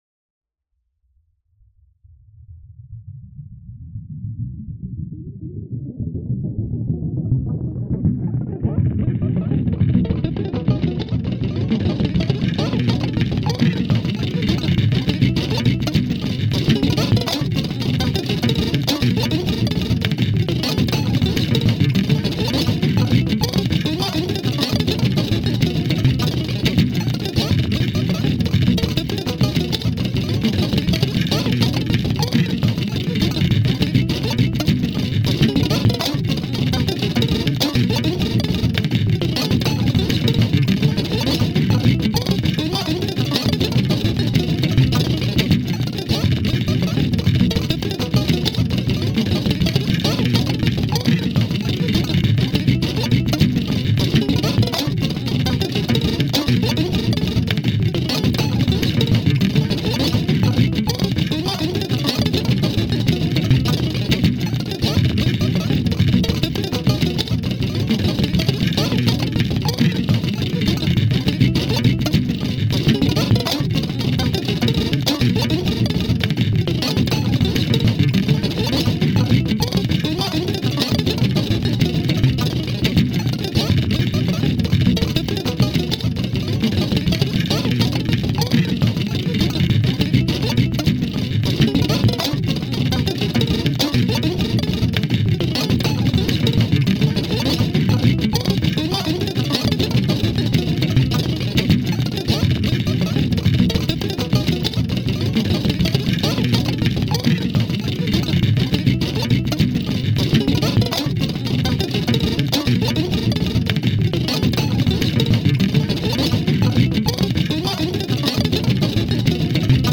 呪術的、儀式的な、プレクティクス・パルス・ミュージック。
パルスによる超感覚的な酩酊感。
パルスにおけるプレクティクス・ギター・オーケストレーション。